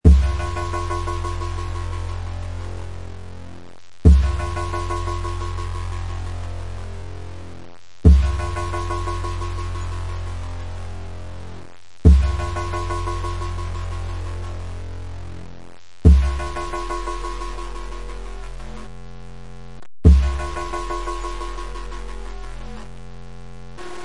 electronic_shock.wav